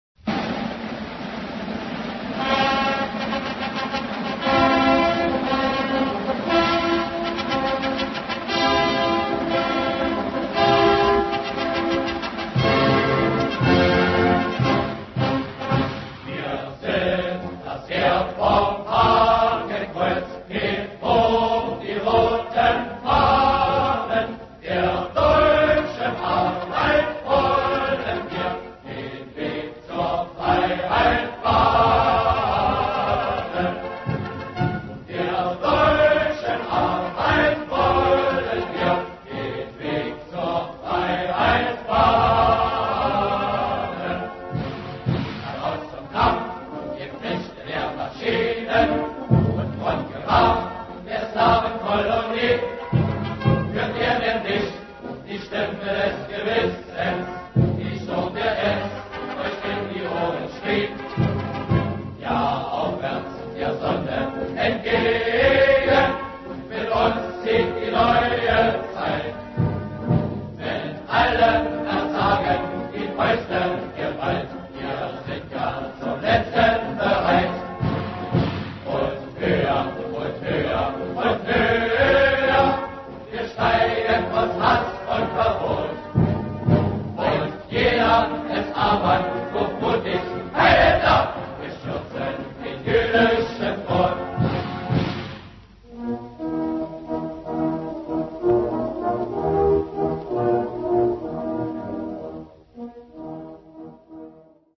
в инструментальном варианте и с участием хора.